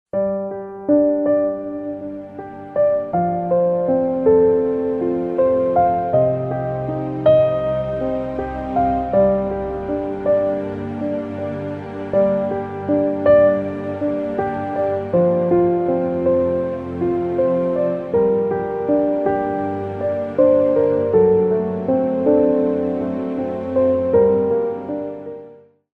زنگ موبایل
زنگ موبایل (بی کلام) محزون و سوزناک